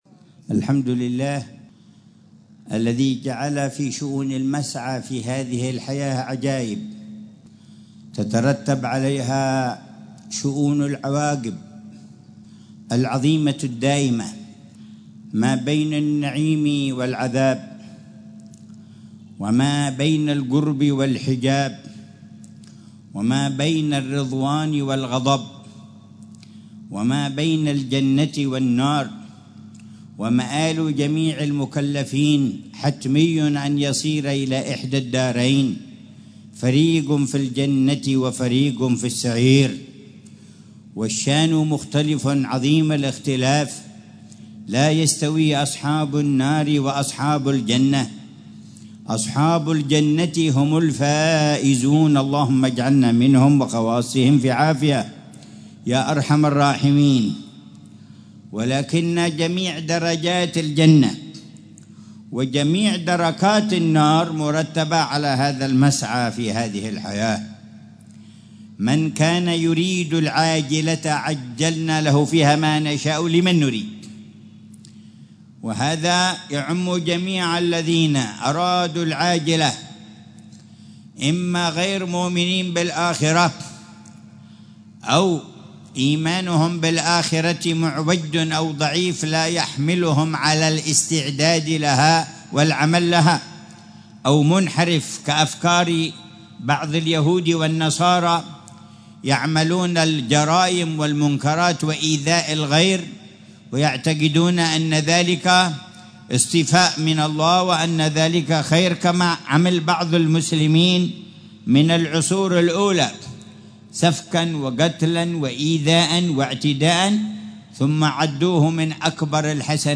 محاضرة العلامة الحبيب عمر بن محمد بن حفيظ في جلسة الجمعة الشهرية الـ57، في الساحة المقابلة لمسجد مولى عيديد مولى القبة، باستضافة حارتي الخليف وعيديد بمدينة تريم، ليلة السبت 16شعبان 1446